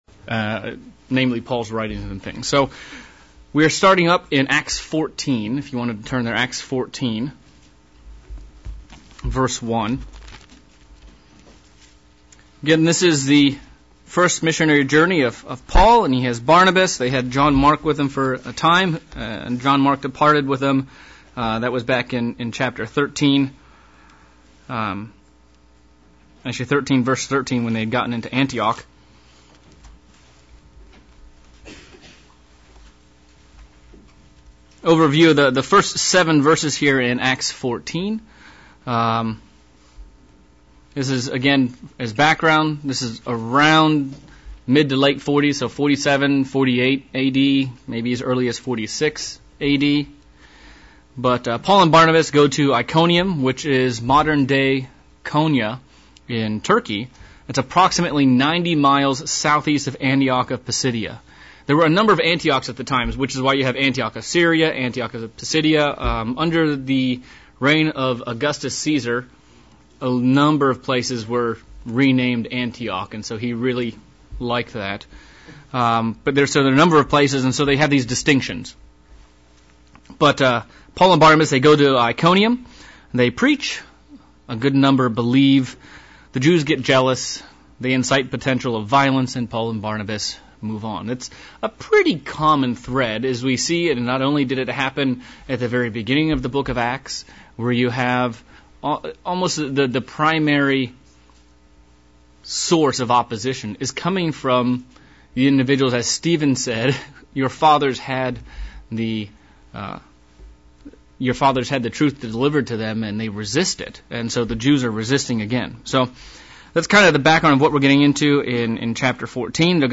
August Bible Study-Acts 14